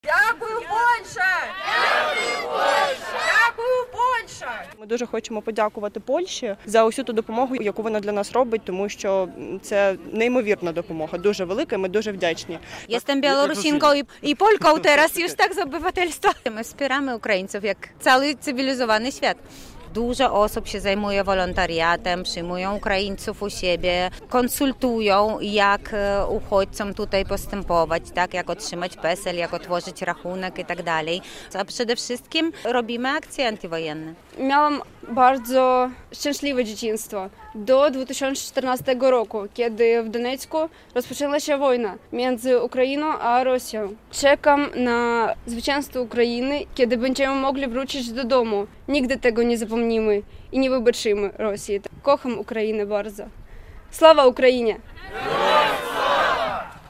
Antywojenna manifestacja w centrum Białegostoku